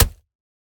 Minecraft Version Minecraft Version 1.21.5 Latest Release | Latest Snapshot 1.21.5 / assets / minecraft / sounds / block / packed_mud / break3.ogg Compare With Compare With Latest Release | Latest Snapshot
break3.ogg